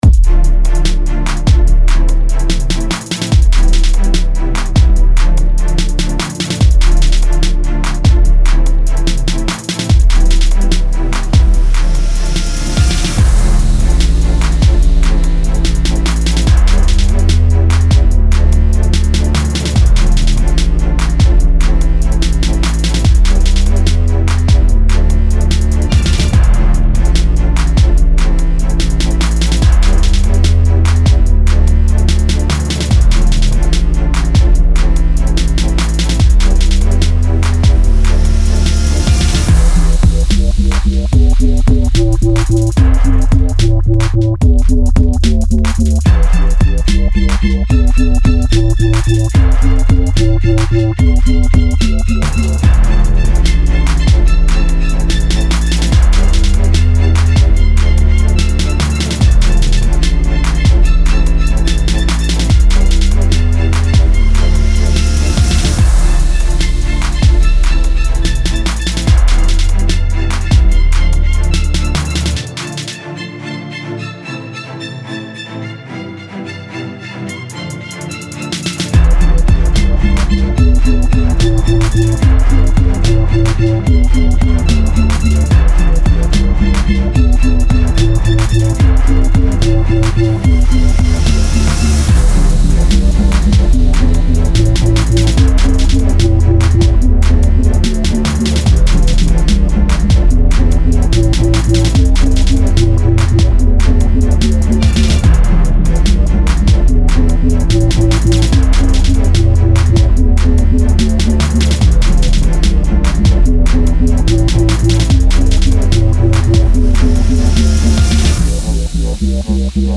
Style Style EDM/Electronic, Hip-Hop, Orchestral
Mood Mood Cool
Featured Featured Bass, Drums, Strings +1 more
BPM BPM 73